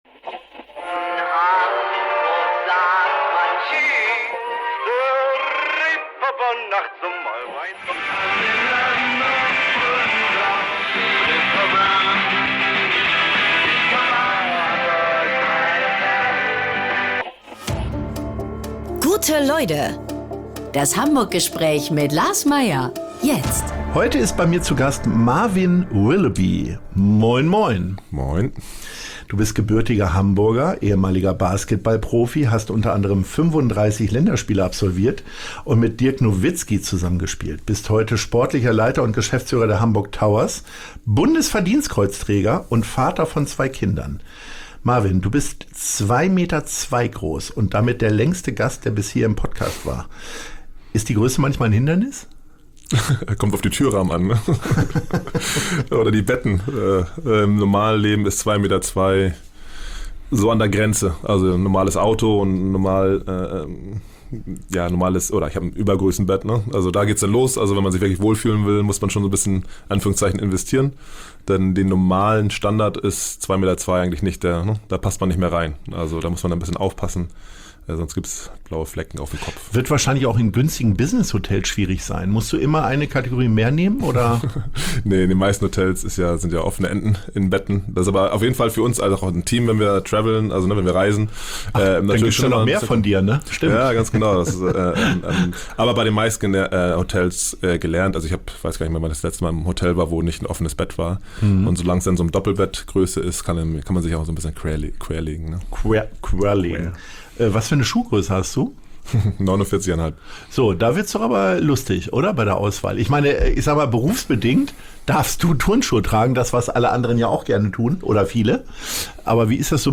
natürlich Corona-konform, im Podcast-Studio begrüßen und mit ihm über Wilhelmsburg, tägliche Herausforderungen und natürlich Basketball sprechen konnte.